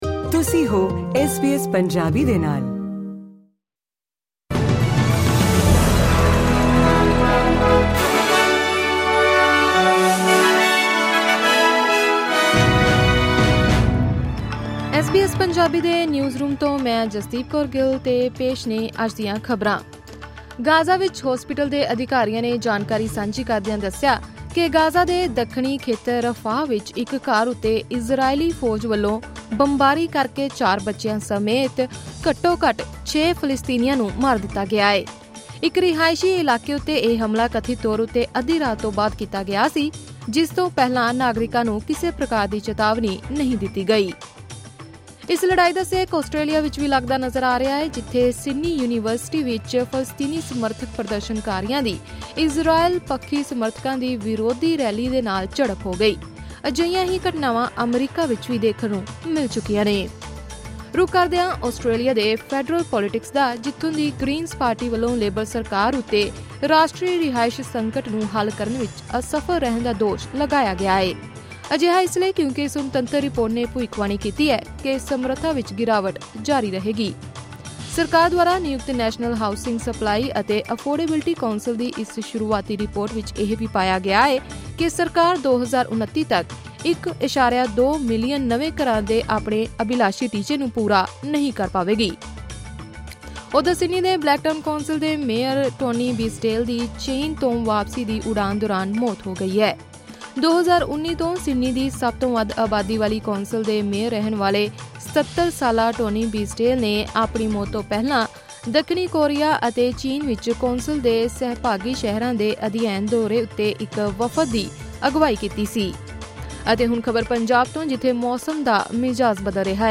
ਐਸ ਬੀ ਐਸ ਪੰਜਾਬੀ ਤੋਂ ਆਸਟ੍ਰੇਲੀਆ ਦੀਆਂ ਮੁੱਖ ਖ਼ਬਰਾਂ: 17 ਮਈ, 2024